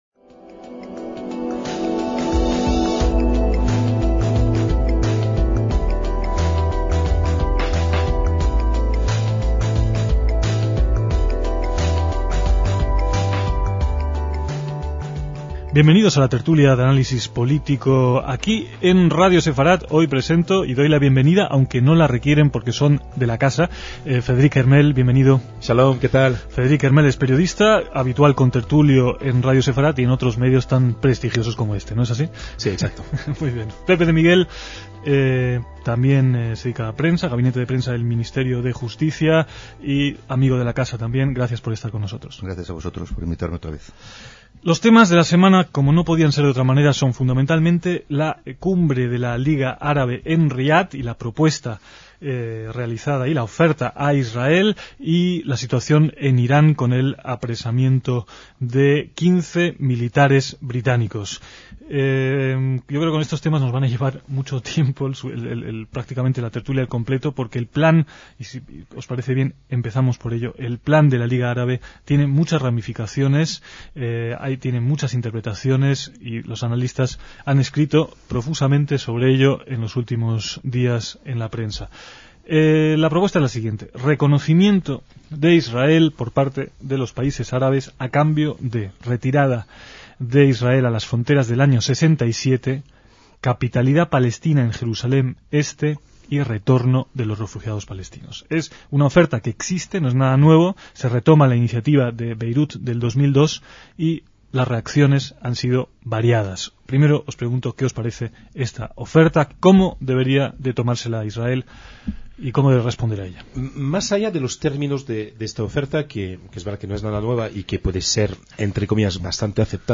Dos temas principales acaparan el debate que reponemos: el Plan de la Liga Árabe e Irán, con los tertulianos